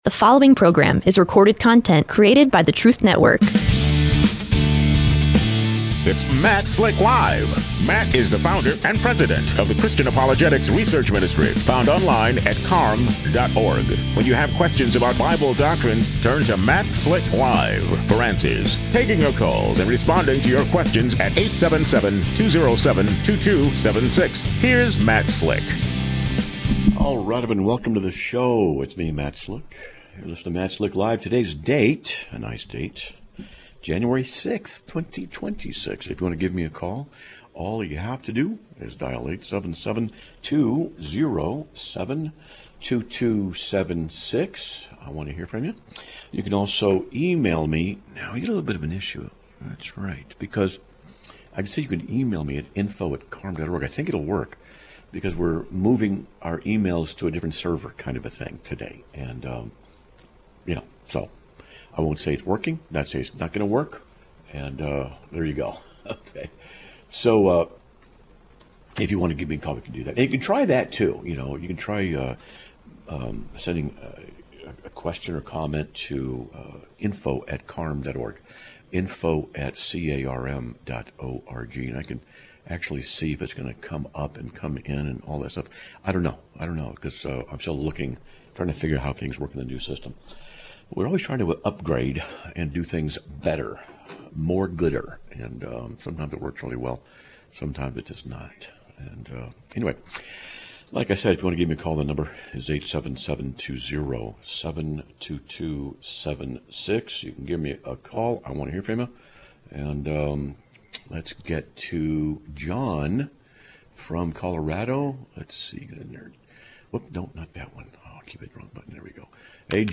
Live Broadcast of 01/06/2026
A Caller with a Late-Life Conversion